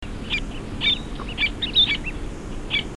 Sterna albifrons
Voce
Un alto e aspro ‘crii-ik’, un ripetuto ‘kik’ e un rapido ‘kirri kirri’.